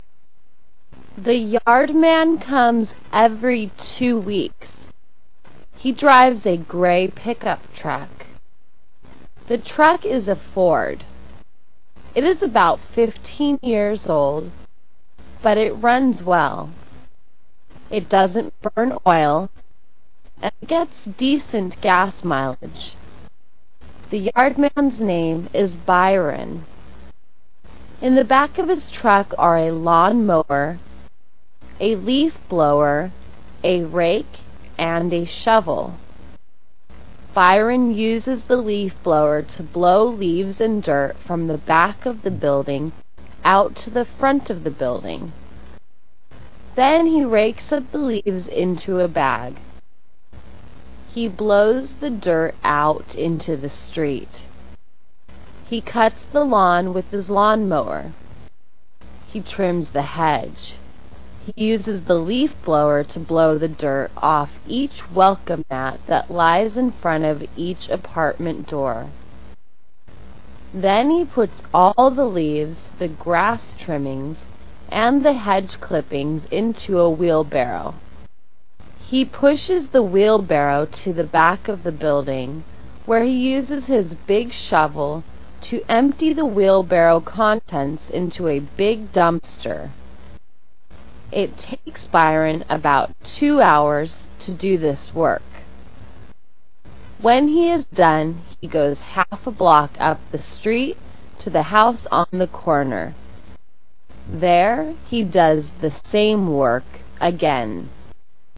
Slow  Stop audio